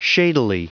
Prononciation du mot shadily en anglais (fichier audio)
Prononciation du mot : shadily